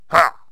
stickfighter_attack12.wav